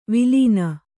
♪ vilīna